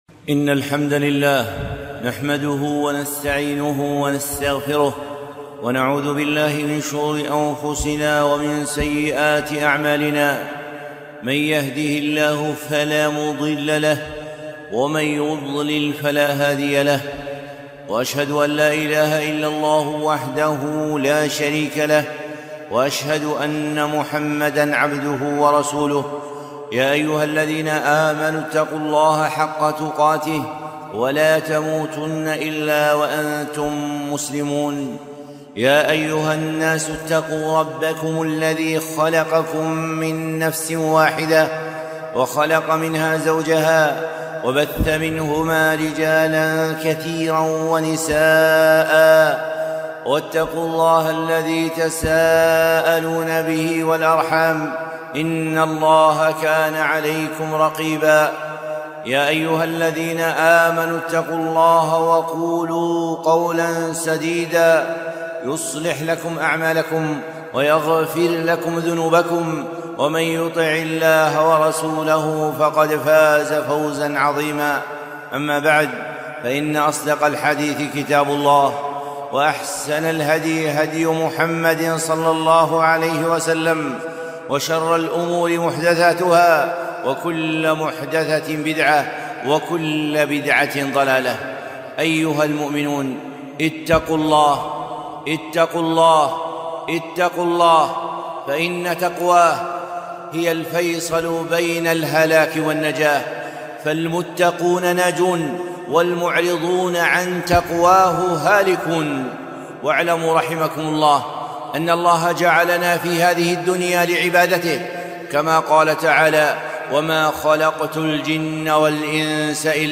خطبة - الاستجابة لله وللرسول ﷺ